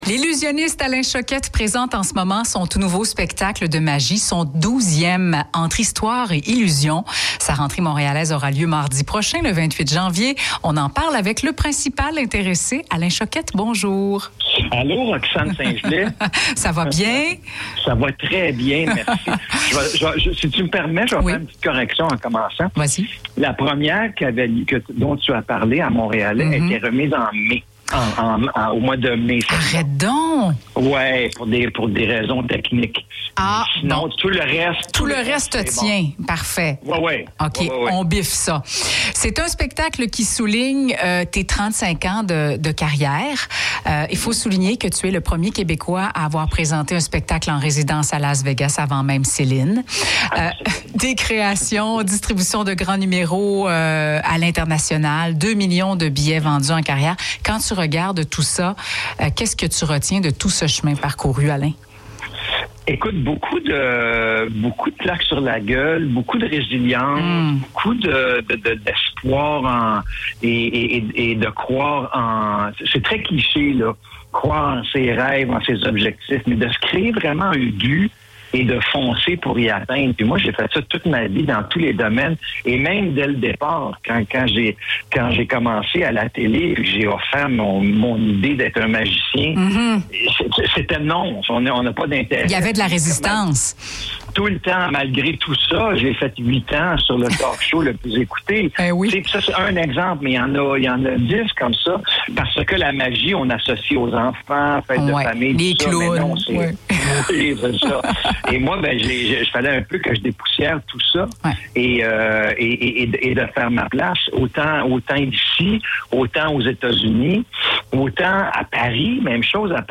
Rejoignez-nous pour cette conversation captivante où magie et souvenirs se rencontrent, et découvrez comment Alain Choquette continue de captiver les publics de tous âges.